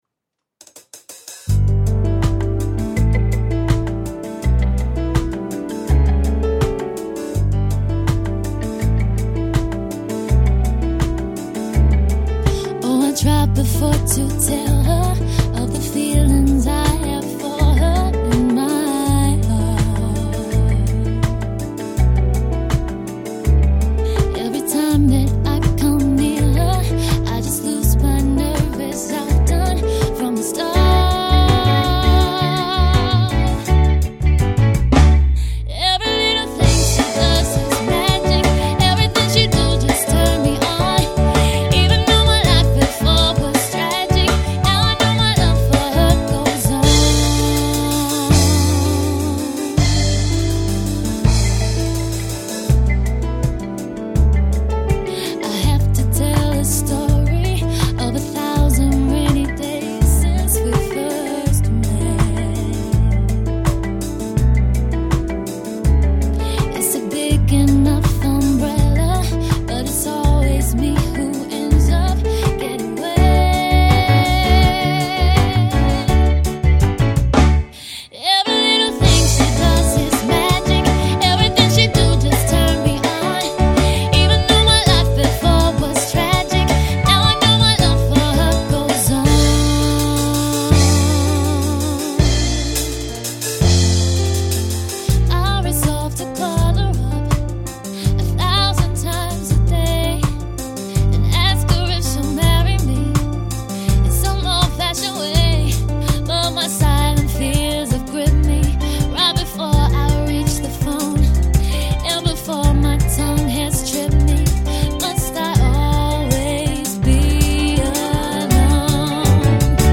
Female Fronted Function Band for Hire